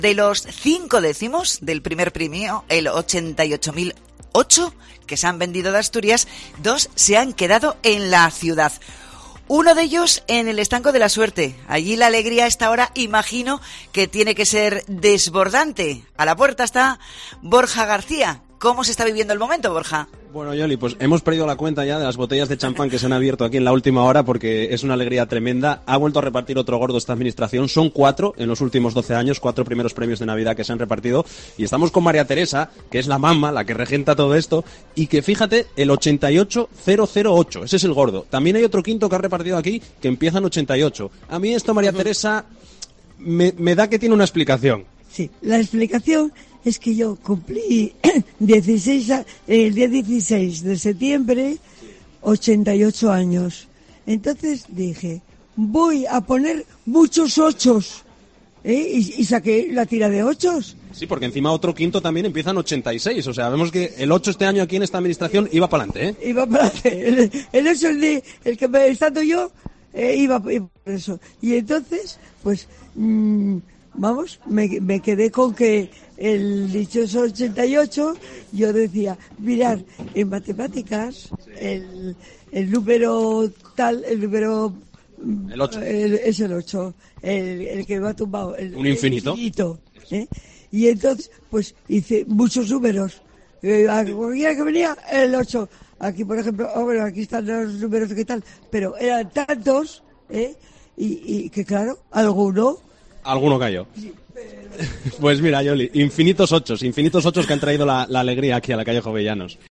El Estanco de la Suerte de Oviedo ha vendido un décimo del Gordo: lo contamos en directo